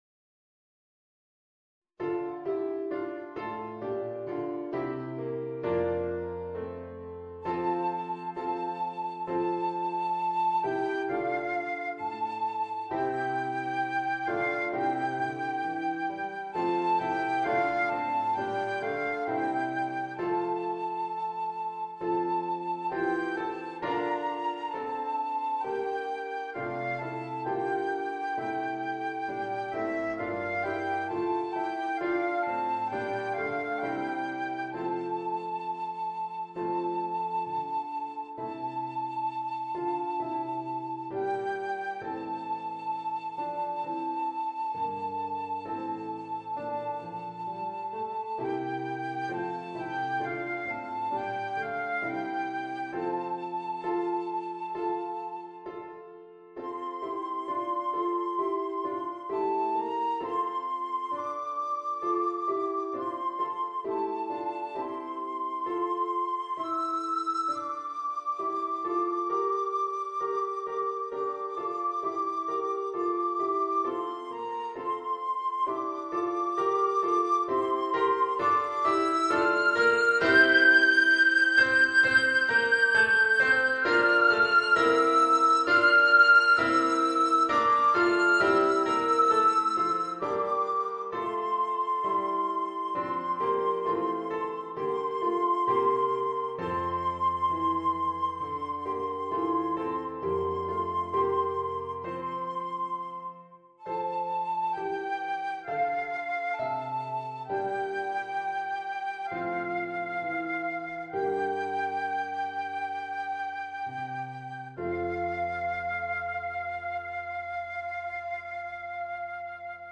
Flöte & Klavier